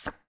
card_illegal.m4a